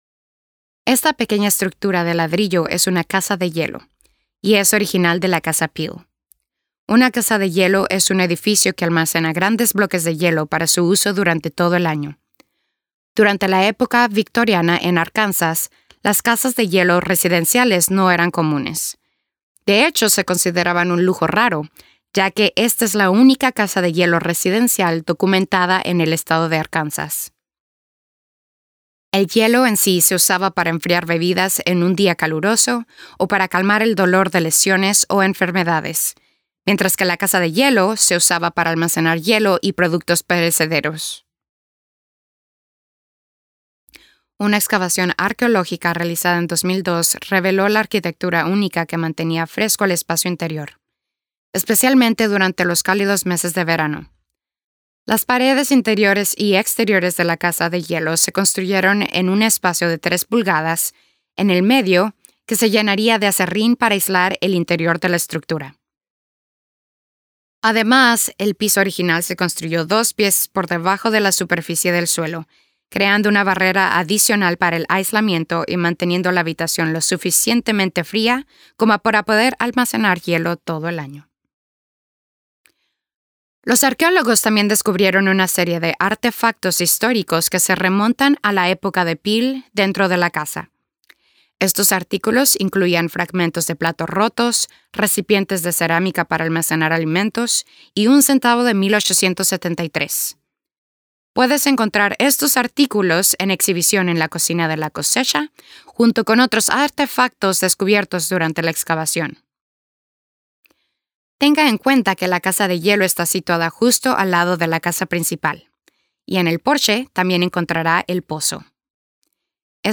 Self-Guided House Tour